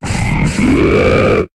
Cri d'Excavarenne dans Pokémon HOME.